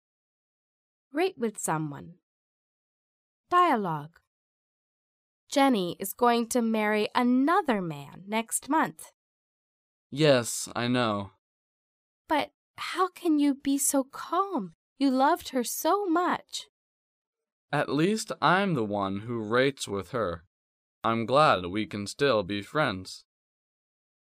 迷你对话学地道口语第297期:受人重视 受人信赖 听力文件下载—在线英语听力室